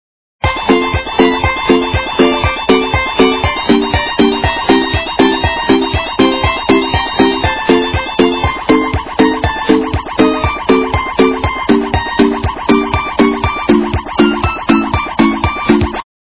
- Шансон